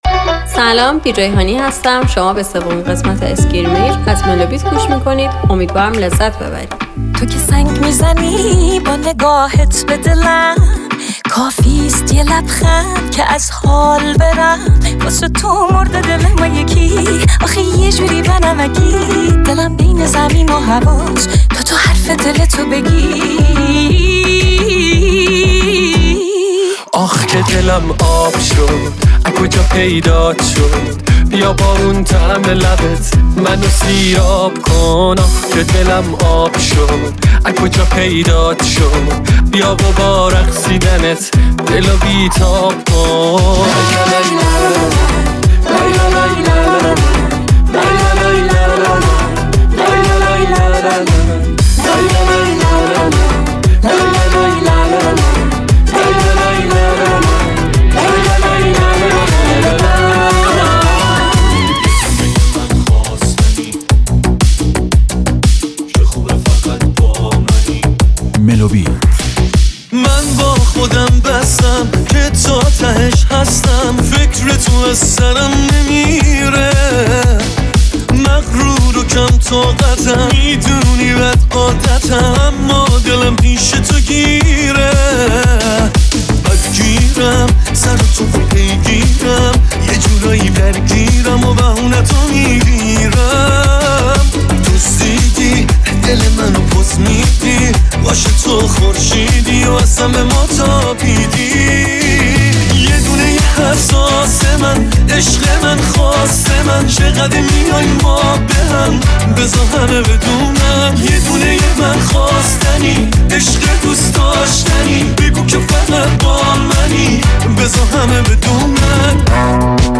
دانلود ریمیکس طولانی پشت سرهم
میکس آهنگ های ایرانی پرطرفدار ۲۰۲۰